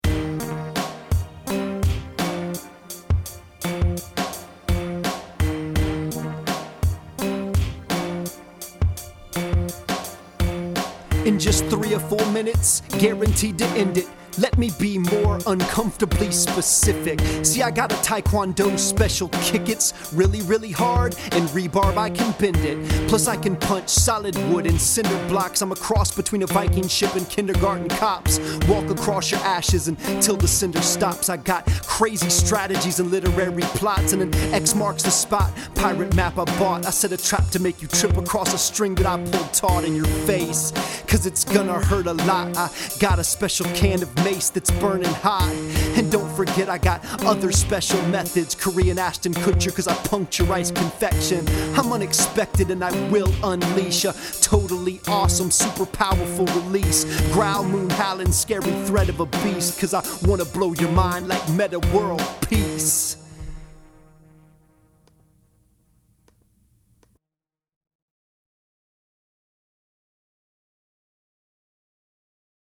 I previously took the liberty of making an English translation of the North Korean threats reduced to music and which also reprises arguably the most famous rap line I’ve ever written (@ 1:40).